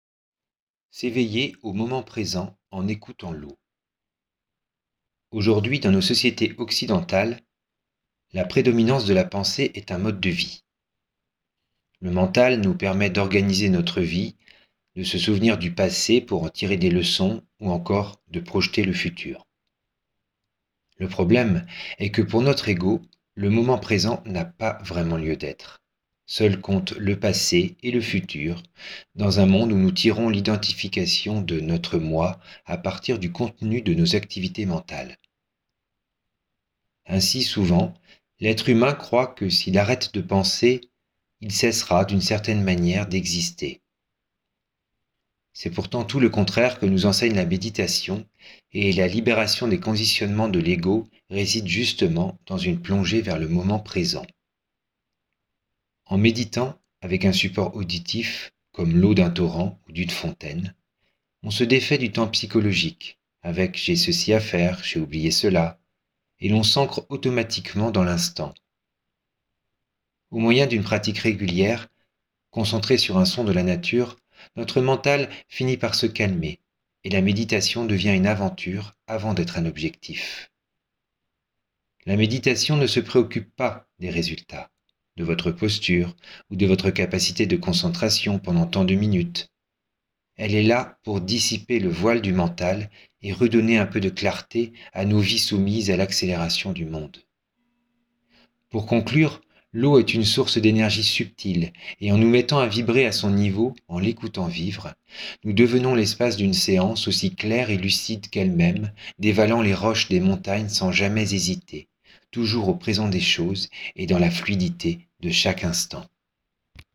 chute d'eau
S_eveiller_au_moment_present_en_ecoutant_l_eau.mp3